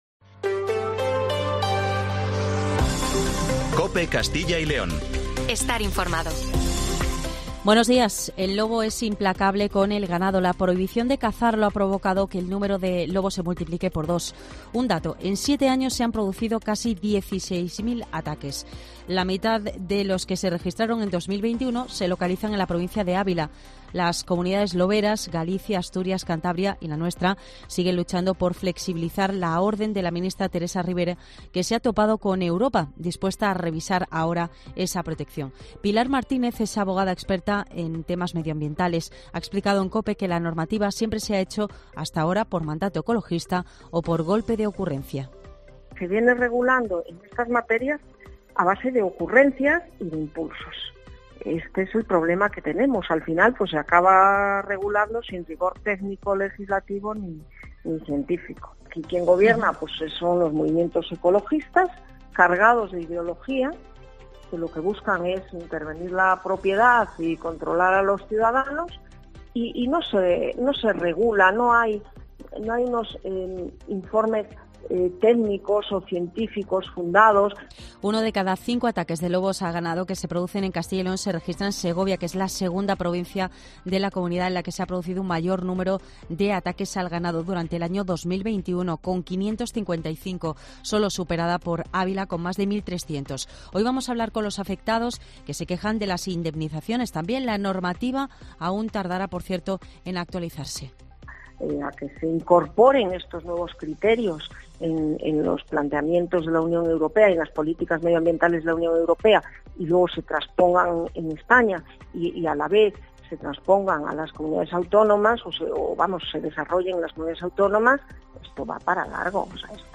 Una abogada explica en Herrera en COPE el largo proceso hasta modificar la normativa del lobo